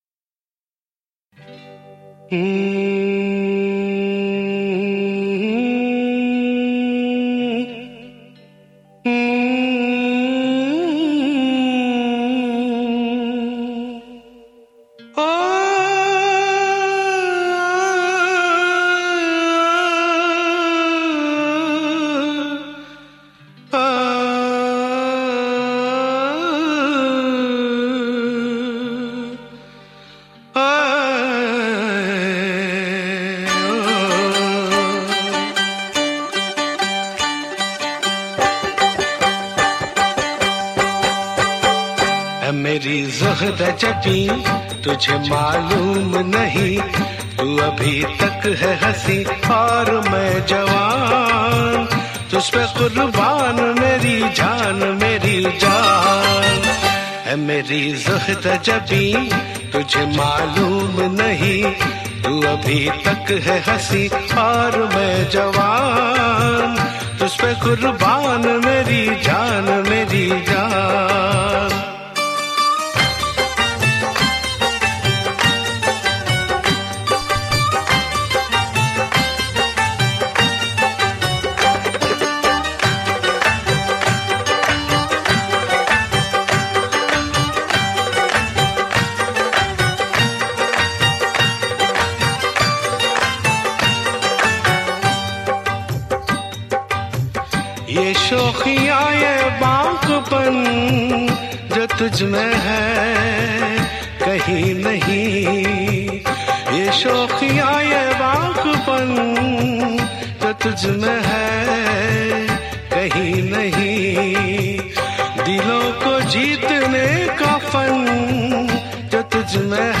Urdu Qawwali MP3